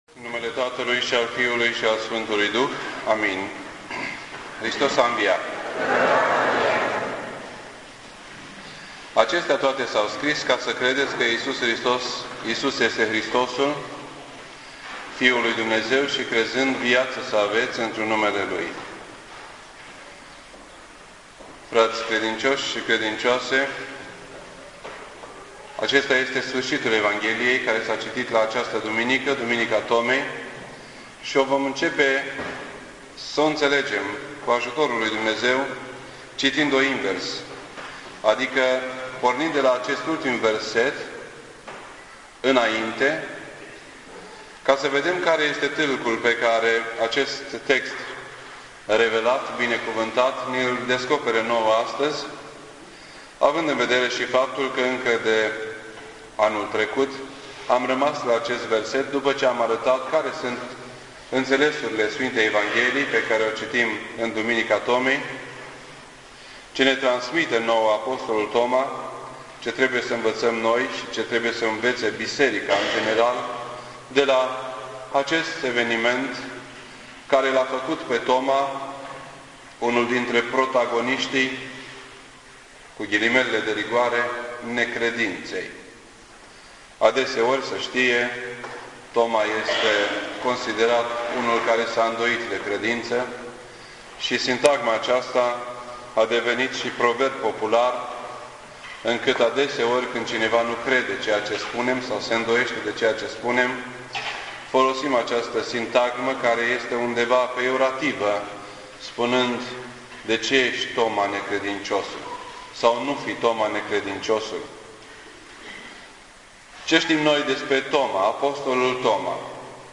This entry was posted on Sunday, May 4th, 2008 at 9:44 AM and is filed under Predici ortodoxe in format audio.